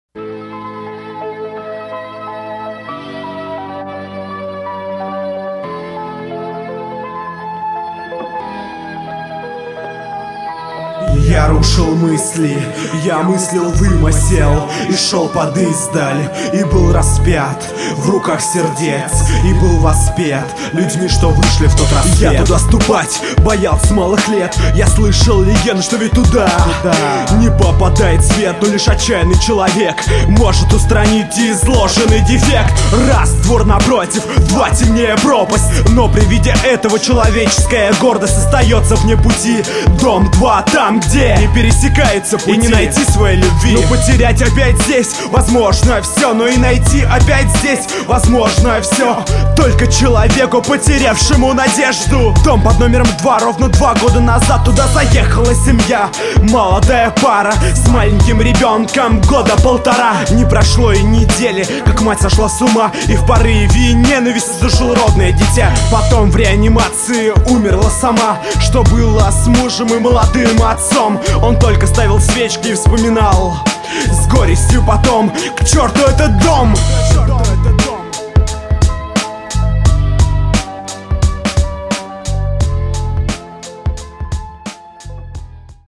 • Жанр: Рэп